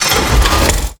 range_laser.wav